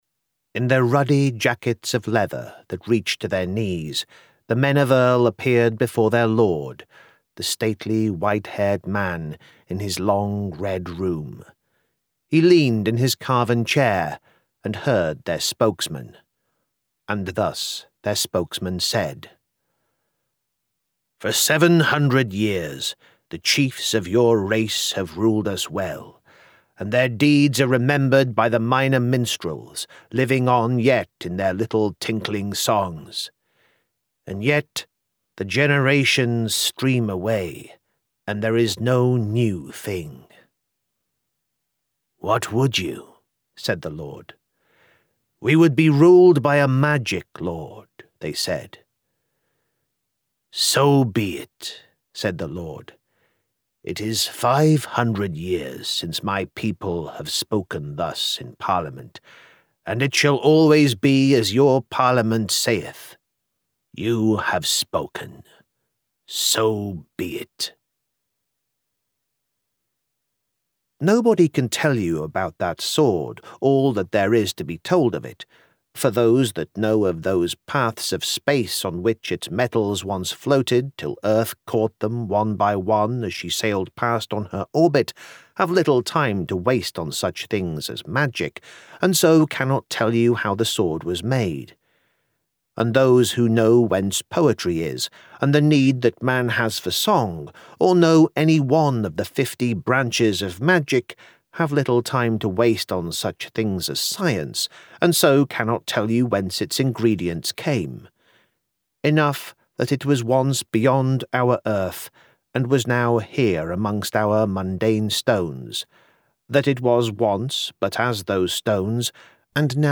A Selection of Audiobook Samples